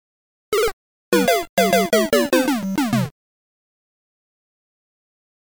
Здесь вы найдете как классические 8-битные эффекты из первых игр серии, так и более современные аудиофрагменты.
Марио — конец игры (смерть или потеря жизни)